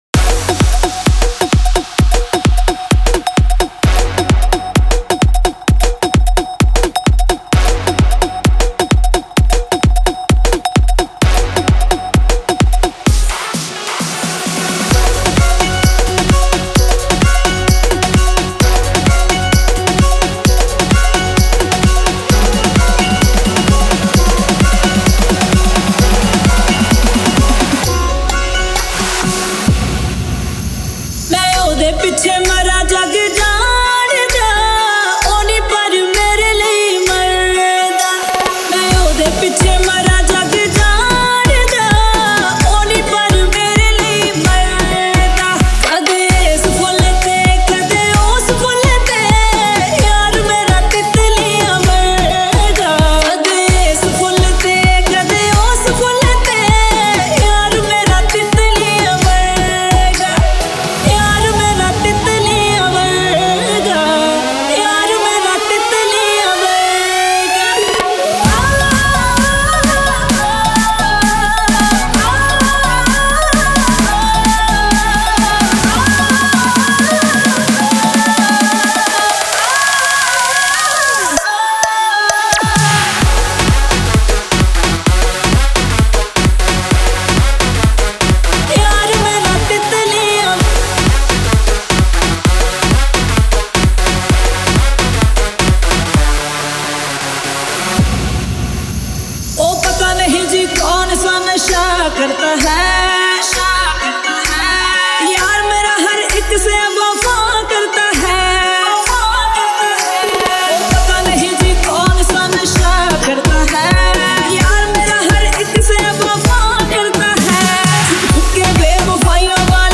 Album : Single DJ Remix